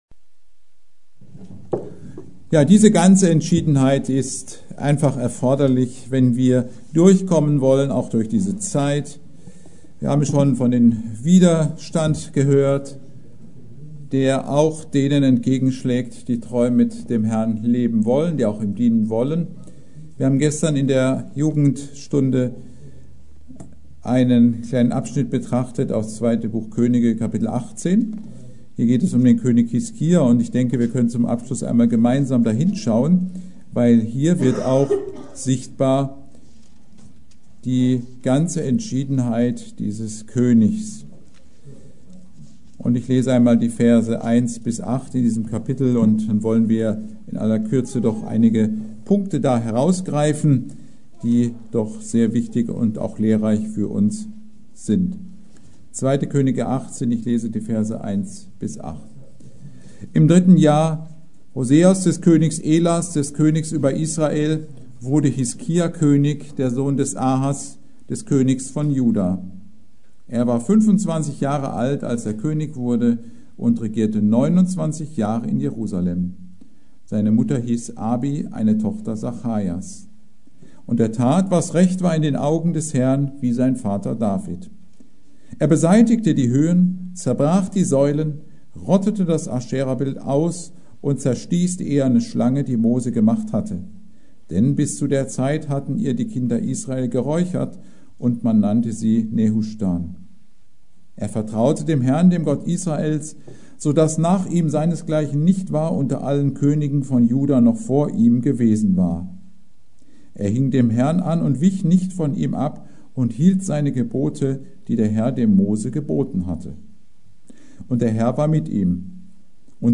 Predigt: König Hiskia – Entschiedenheit